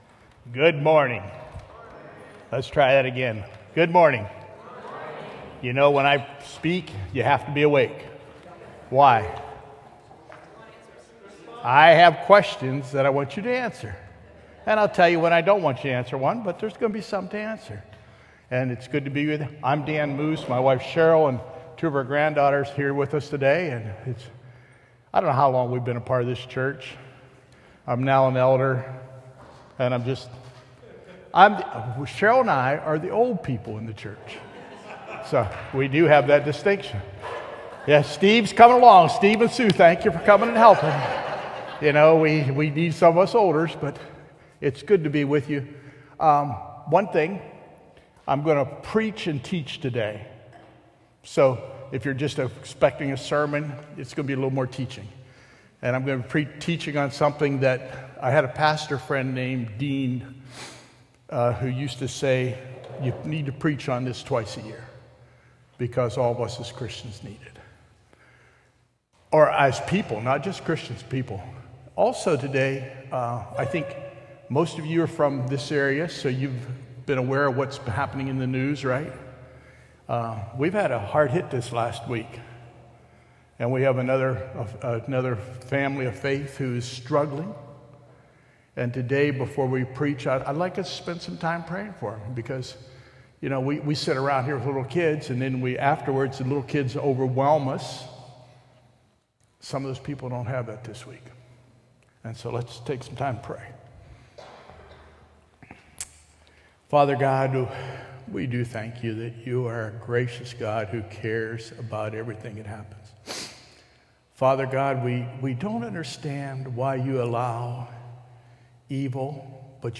Sermon: Forgiveness: A Key to Freedom in Christ
sermon-forgiveness-a-key-to-freedom-in-christ.m4a